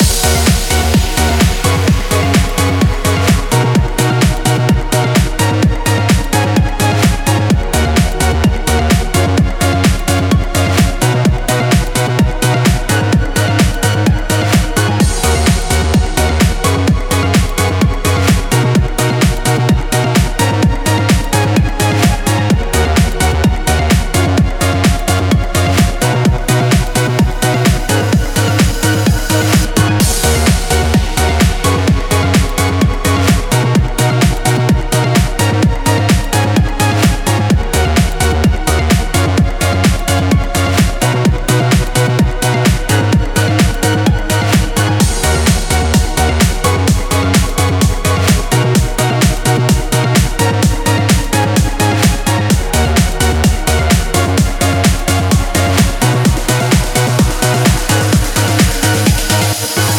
EDM（エレクトロニック・ダンス・ミュージック）のループ音楽素材Track1の8パターンです。